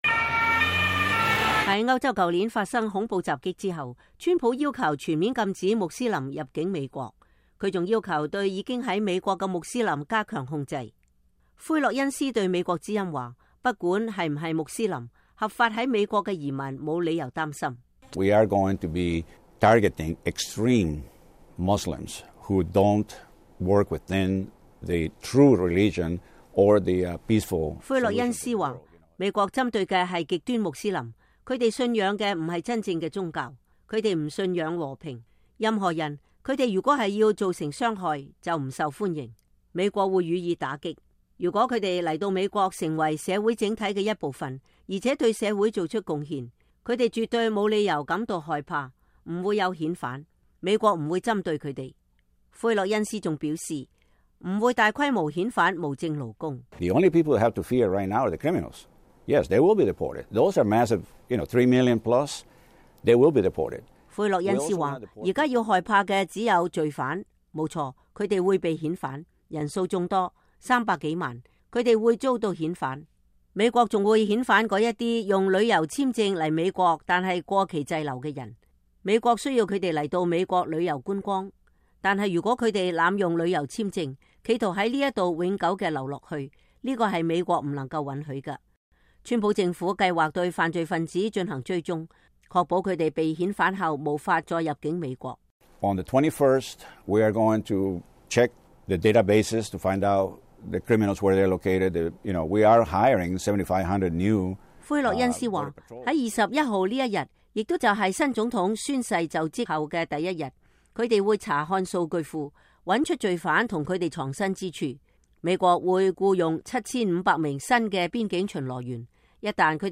專訪：只有罪犯才需害怕遣返